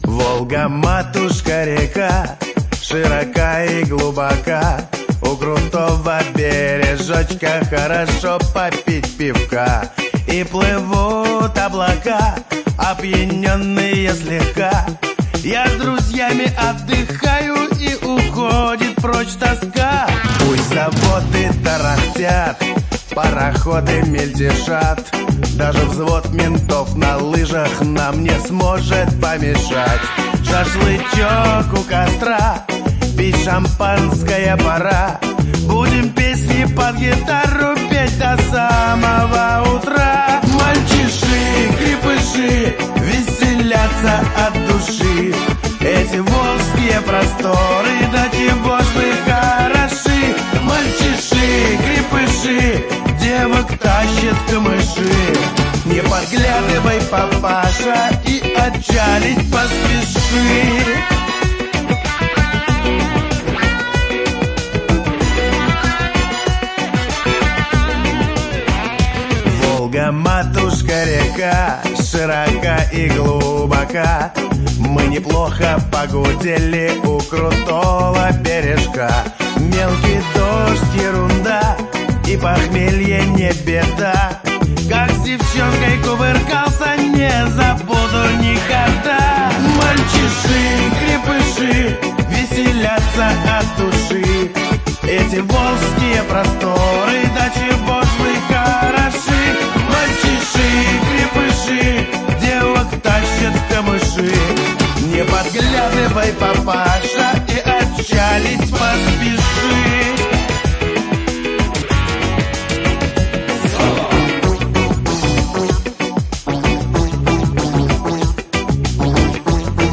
шансон